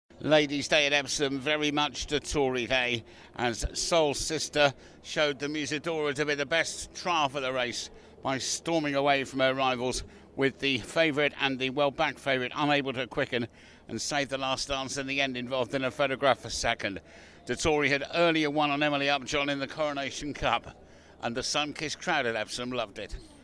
reports from Epsom Race track.